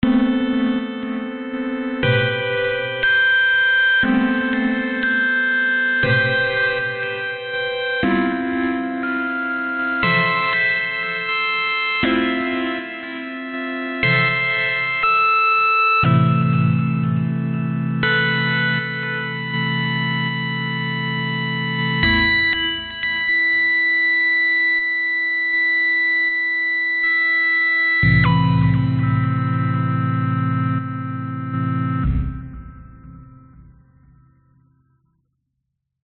标签： moog_rogue theremin oberheim yamaha_cs60 rapture mu3eum soft_synth chords a_minor experimental
声道立体声